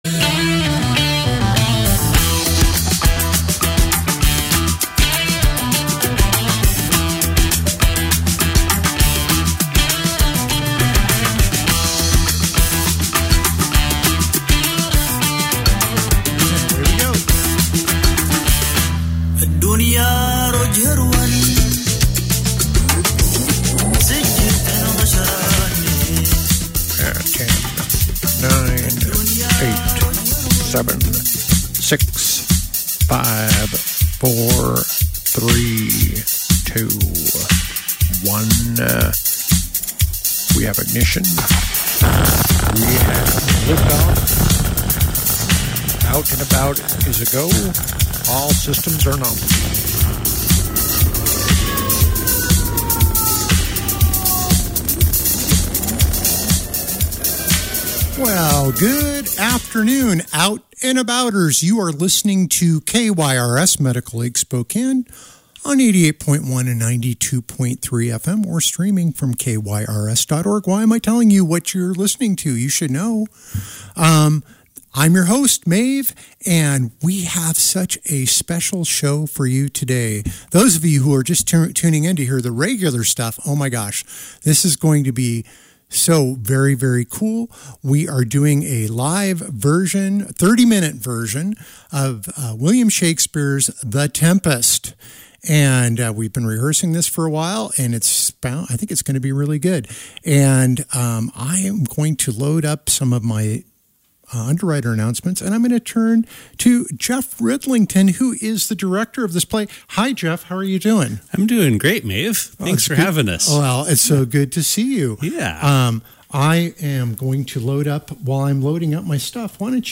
Yesterday's Out and About - The Inland Classical Theatre Radio Players perform a live, thirty-minute production of The Tempest!
The play lasted about 25 minutes actually. So because we had extra time, we have a little Q and A at the end too.